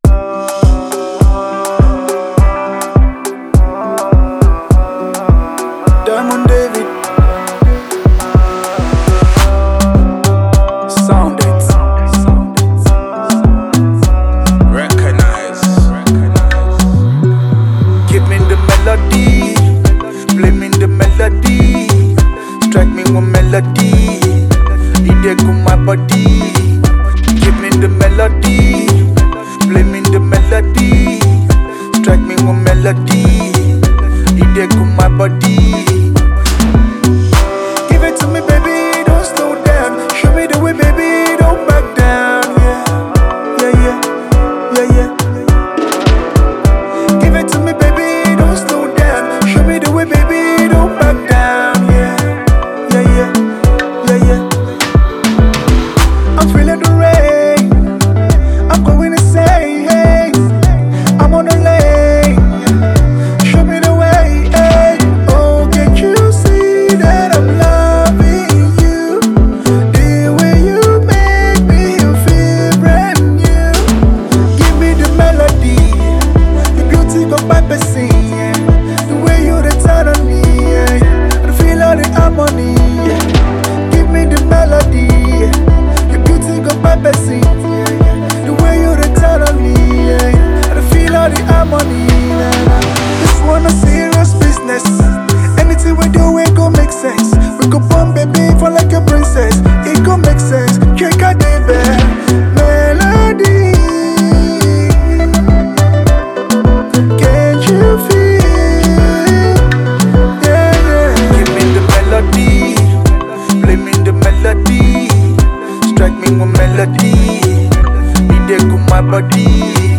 AFRO POP
club banging and emotional love song